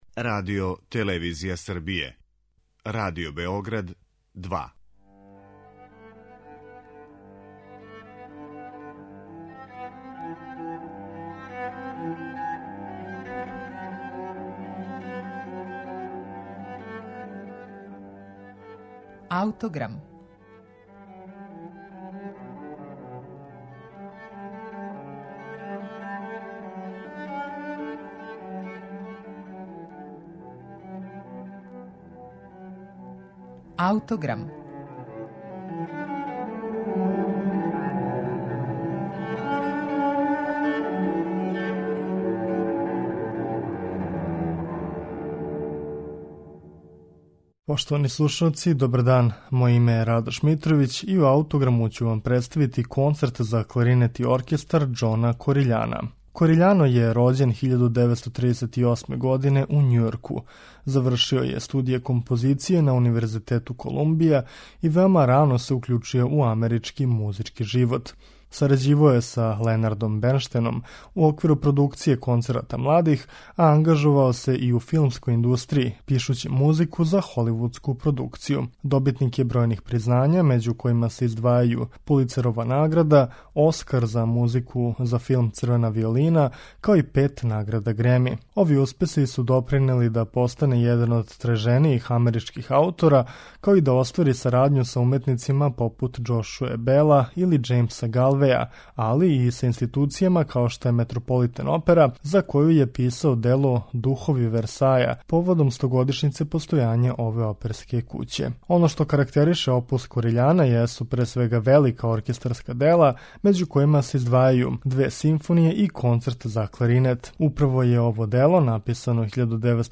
стилски еклектична композиција